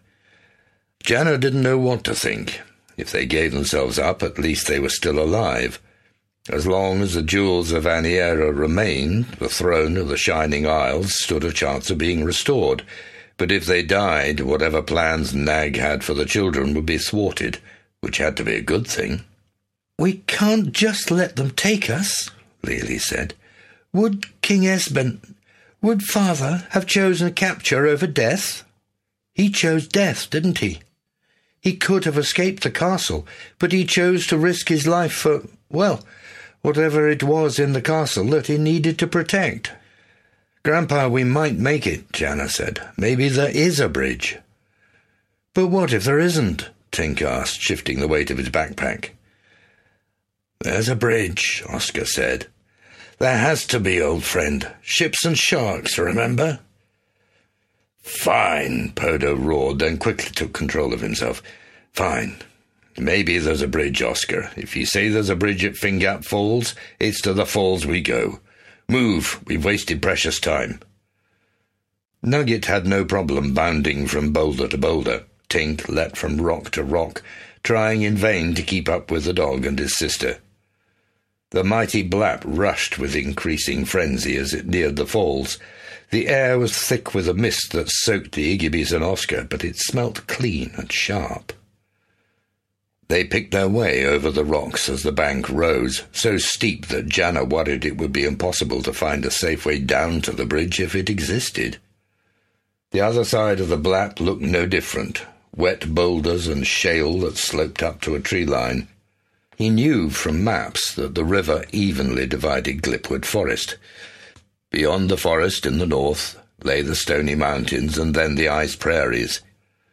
North! Or Be Eaten (The Wingfeather Saga, Book #2) Audiobook
8 Hrs. – Unabridged